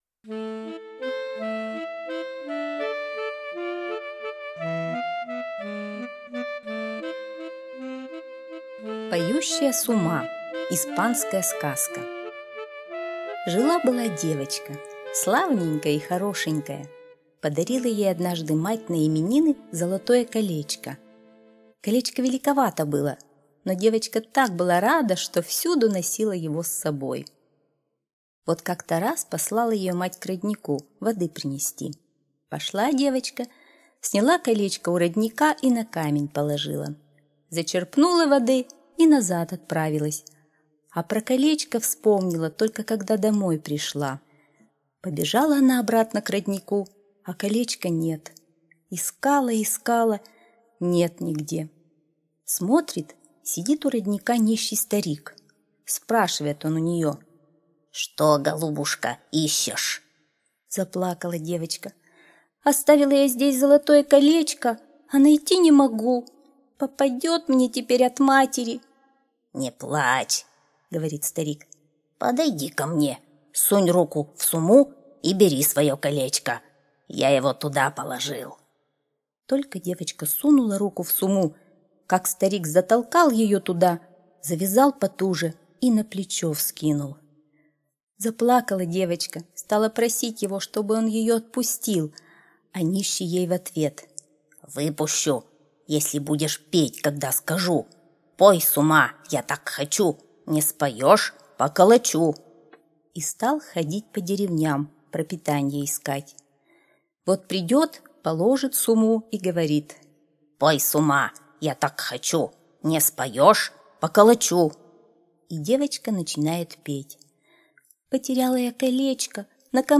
Поющая сума - испанская аудиосказка - слушать